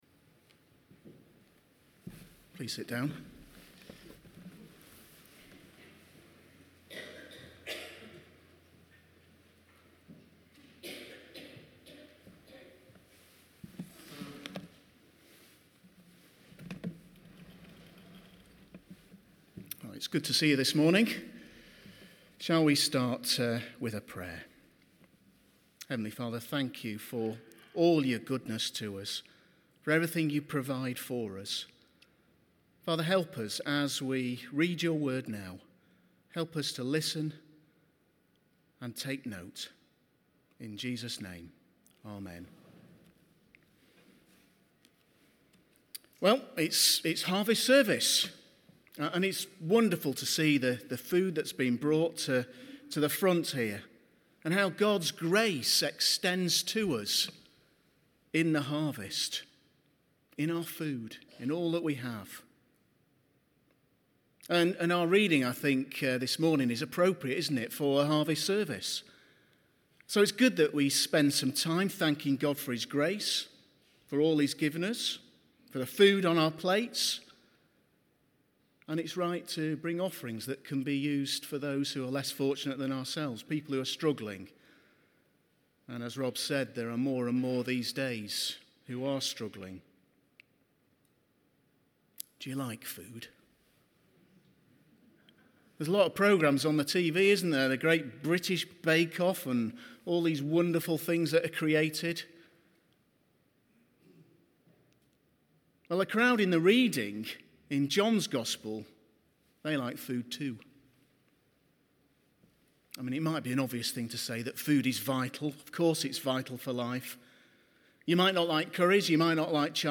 These sermons are those which are not part of any series and are taken from the Sunday services at St George’s during 2013,